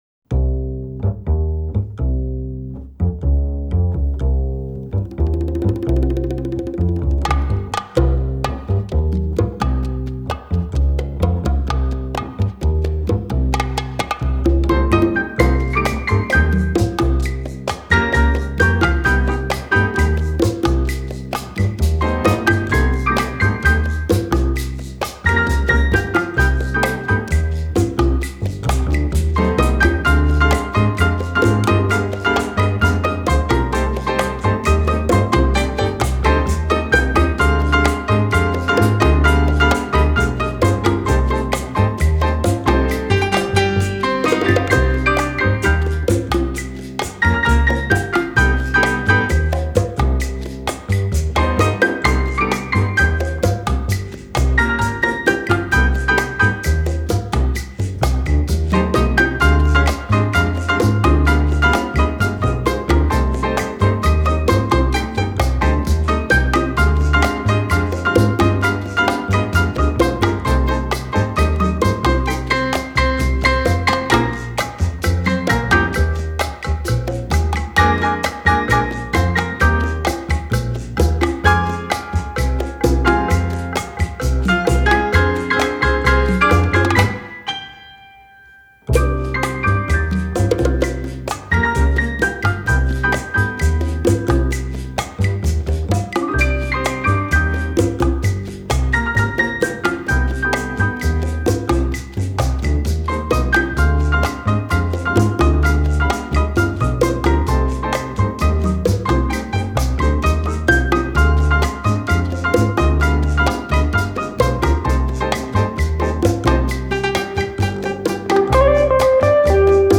Genre: Jazz
酷酷的拉丁爵士樂
我最喜歡他開頭的層次感，一開始先用Bass帶出一點神祕感，接下來是康加鼓漸強進場，最後是用鋼琴帶出主題。
Recorded at Stiles Recording Studio in Portland, Oregon.